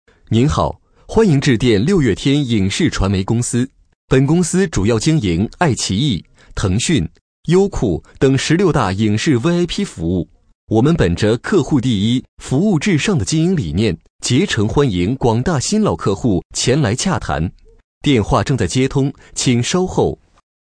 【男6号彩铃】商务温馨3号
【男6号彩铃】商务温馨3号.mp3